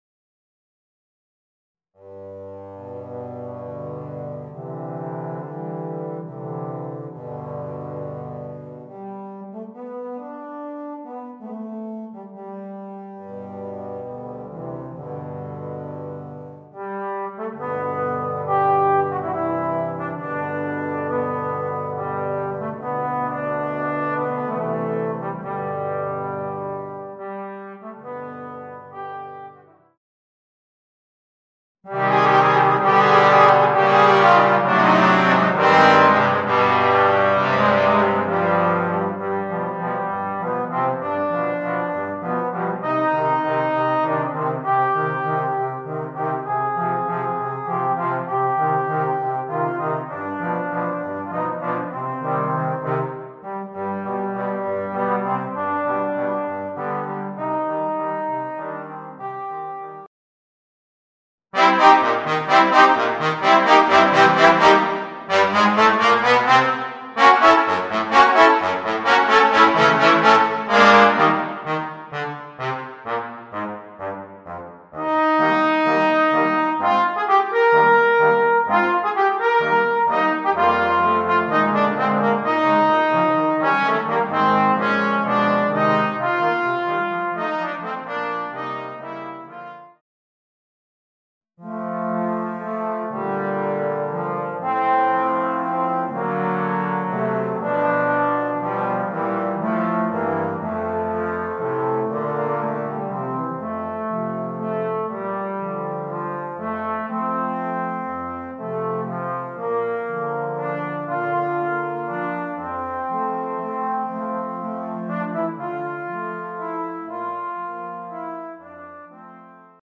Voicing: 4 Trombones